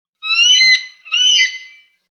Águila Arpía (Harpia harpyja)